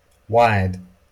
IPA/waɪd/